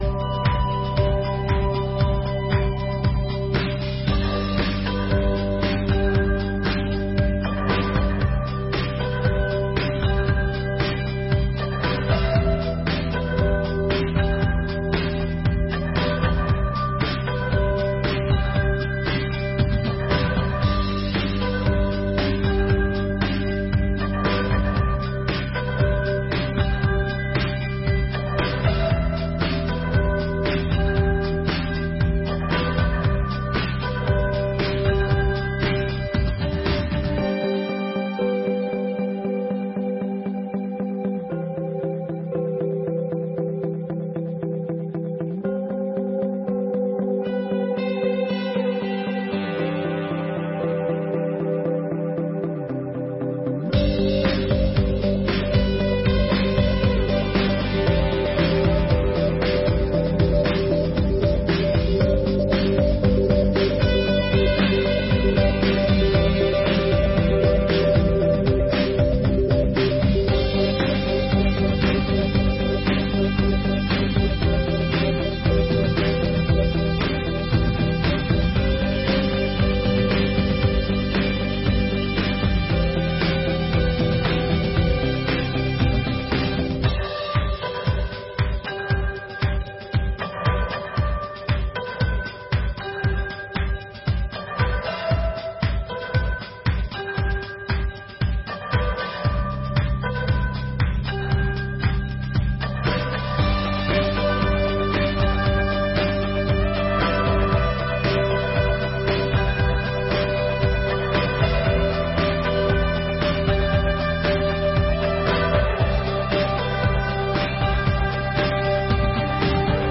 2ª Sessão Ordinária de 2023